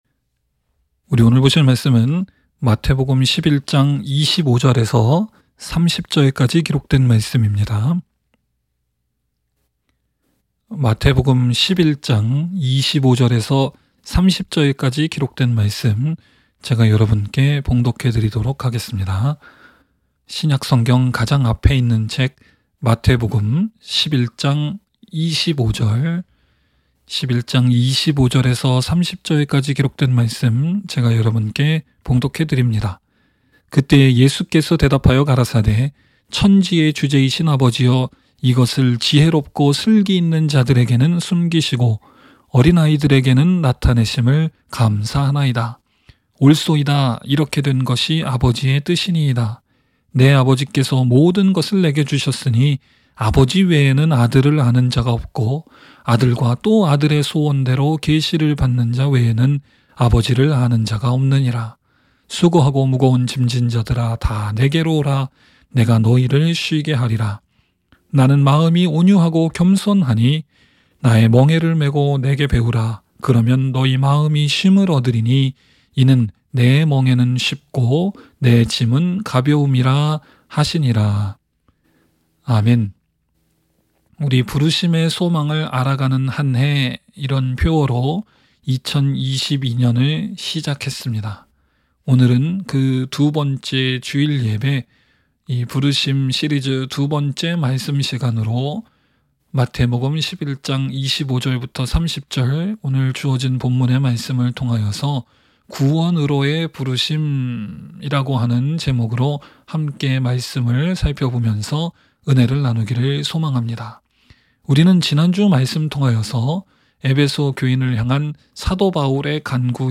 by admin-new | Jan 10, 2022 | 설교 | 0 comments